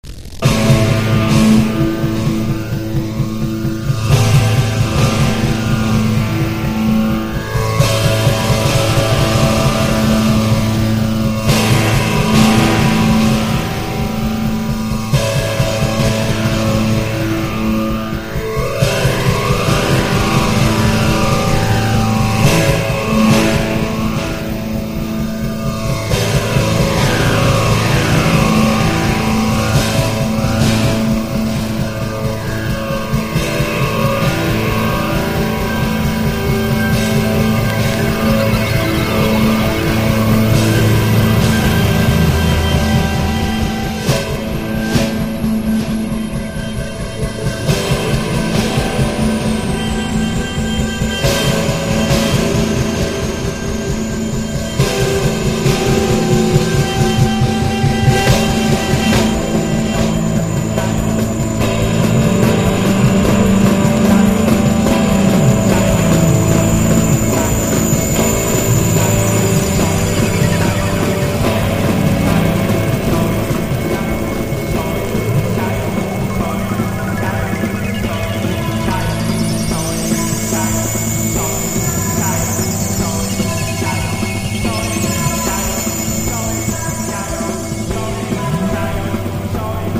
PUNK / HARDCORE
長尺ながら全く落ちることのないパワーと時間を忘れさせるほどの展開力、ドラムセット3台と轟音のギター&ベース